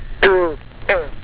Frog
FROG.wav